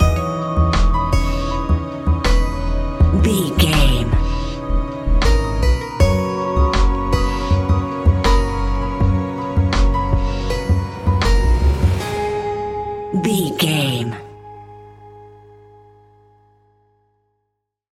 Ionian/Major
C♯
chilled
laid back
Lounge
sparse
new age
chilled electronica
ambient
atmospheric
morphing